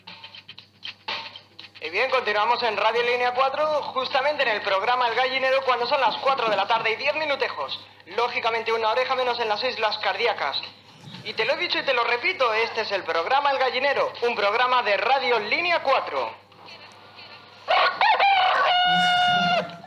Identificació del programa i hora
Entreteniment
FM